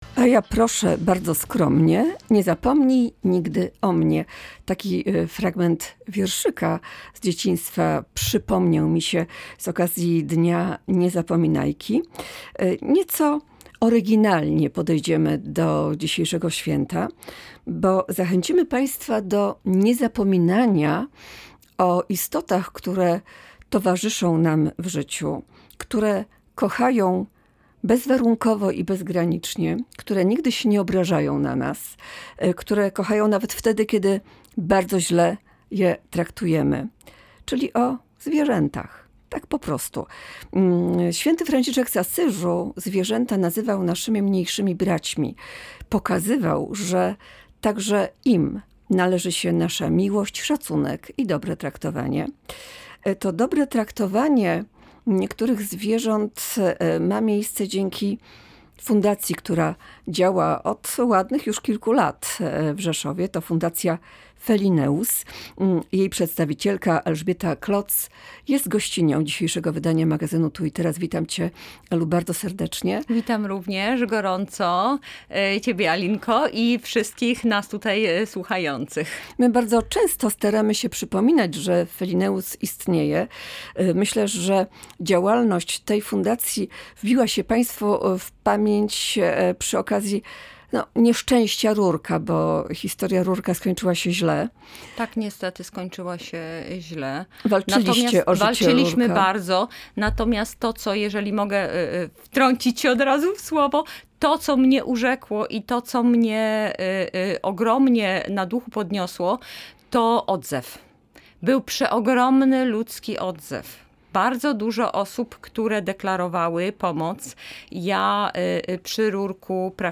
LIVE • W audycji "Tu i Teraz" nietypowo nawiązaliśmy do Dnia Niezapominajki.
Rozmowę